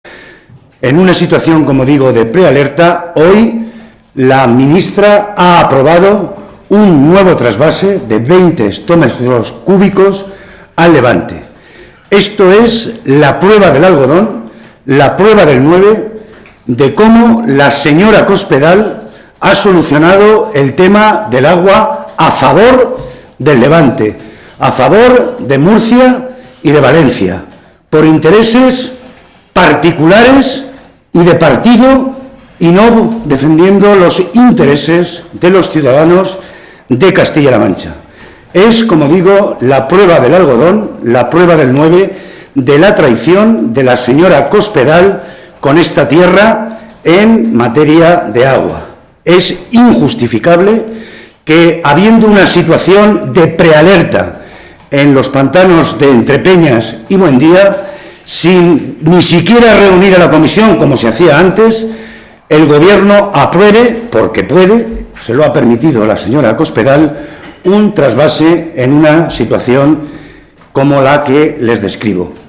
Tierraseca se pronunciaba de esta manera hoy, en el Parlamento autonómico, en declaraciones a los medios de comunicación durante la celebración del Pleno de Presupuestos regionales del año 2015.
Cortes de audio de la rueda de prensa